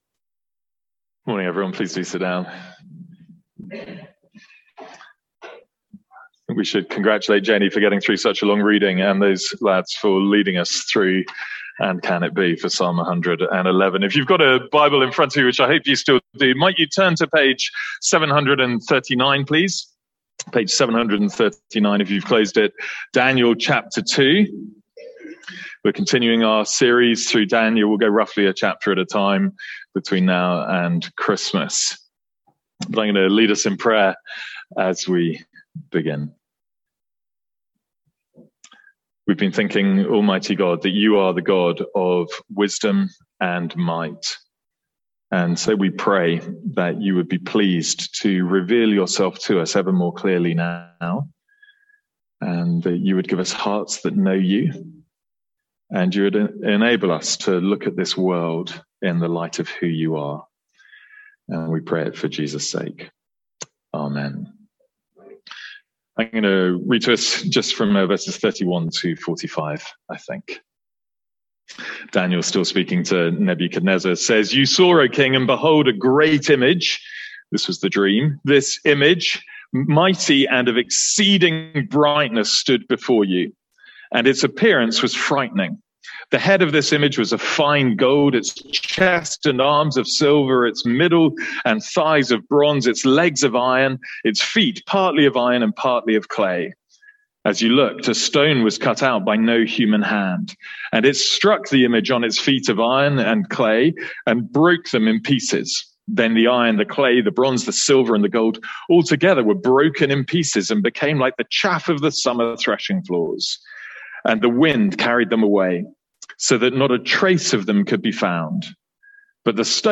Sermons | St Andrews Free Church
From our morning series in Daniel